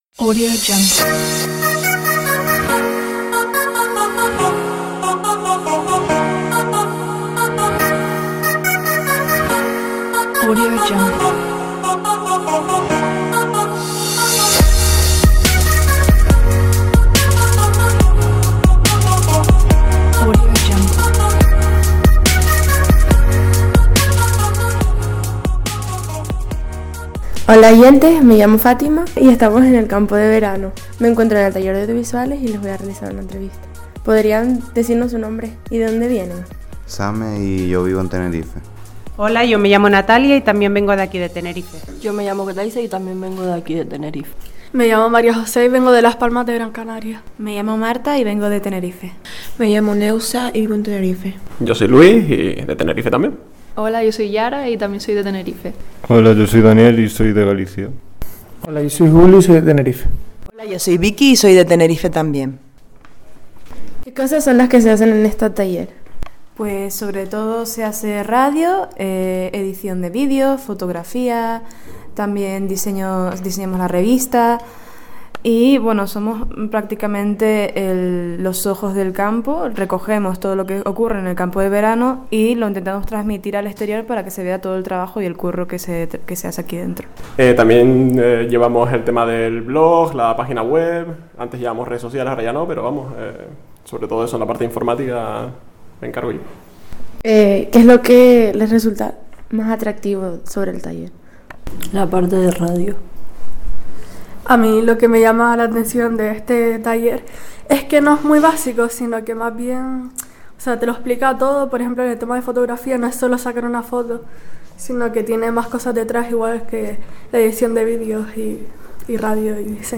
Entrevista-Audiovisuales-R.-1
Entrevista-Audiovisuales-R.-1.mp3